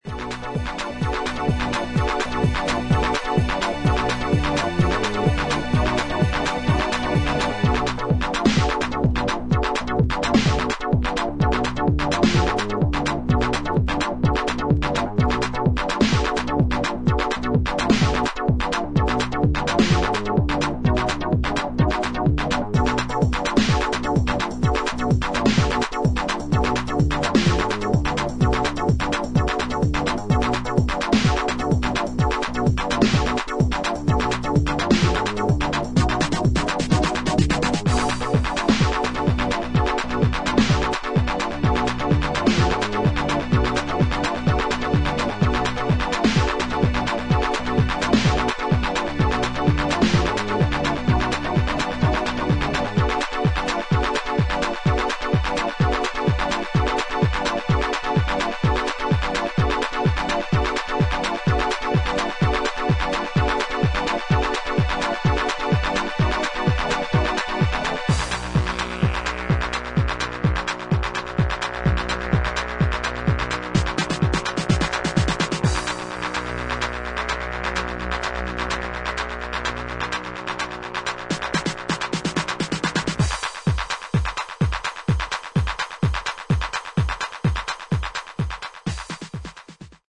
シンプルな編成ながら深みが感じられるエクスペリメンタル的要素が垣間見える90"テクノ傑作